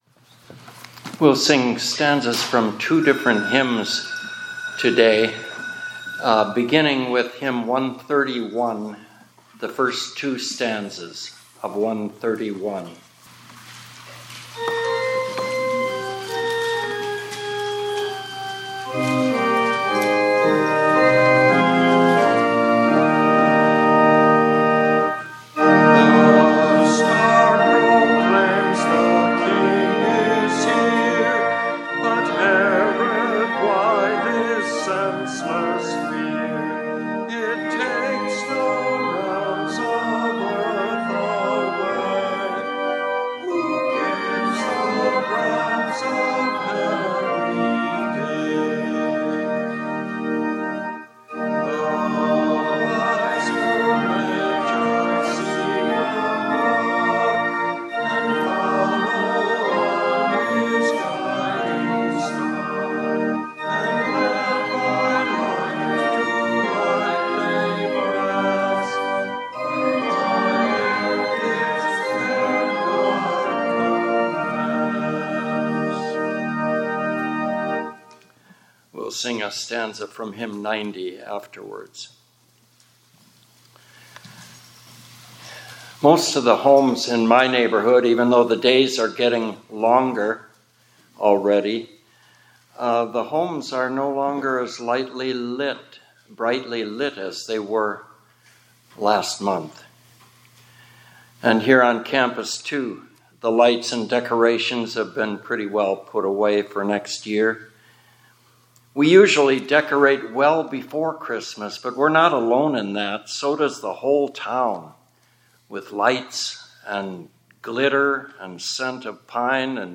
2026-01-15 ILC Chapel — Herod KNEW . . . And So Do You